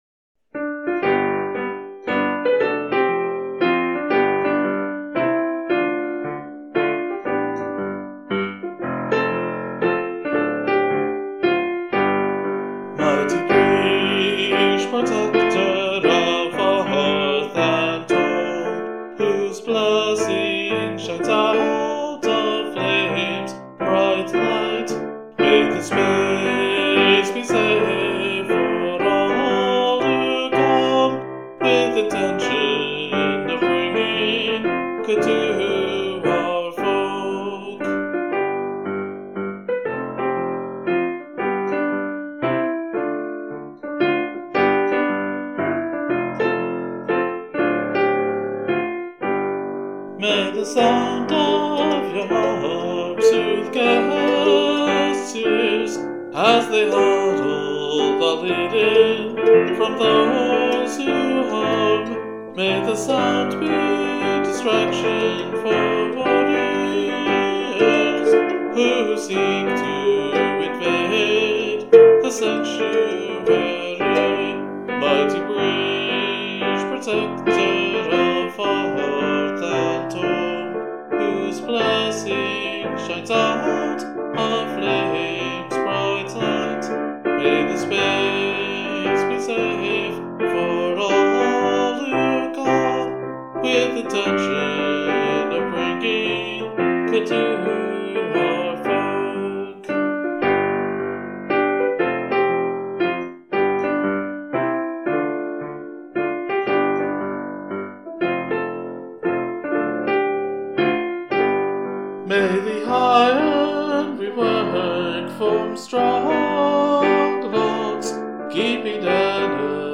Musically, the phrase "gospel swing" is doing a lot of work.
But the way it's written is somewhat close to the right performance, so it's OK to think of it as having a bit of swing to it.
The scale in use here, G mixolydian, is like G major but has an F natural, which adds a haunting D minor as an important chord to the overall positive sound, and also throws in a bit of the Appalachian flat 7 major chord that most would recognize from old-timey and bluegrass.